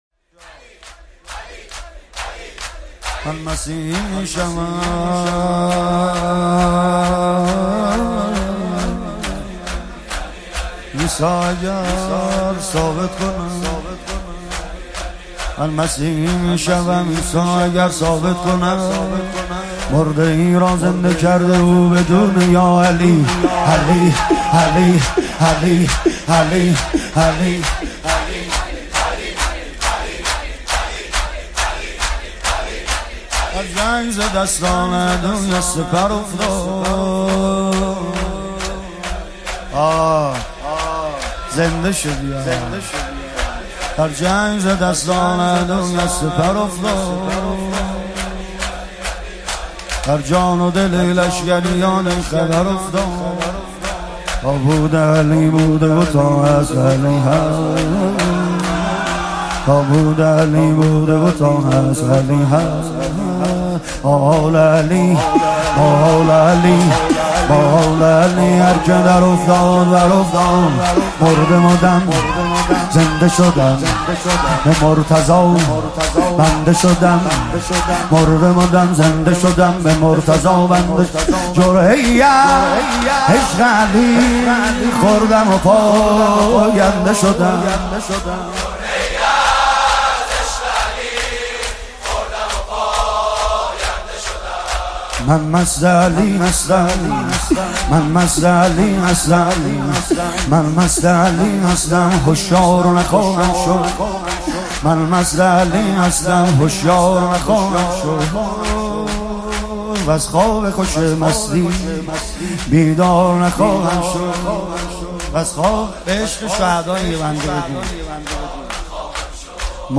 مداحی جدید کربلایی سید رضا نریمانی مراسم هفتگی شام وفات حضرت فاطمه معصومه (س) 27 آذر 1397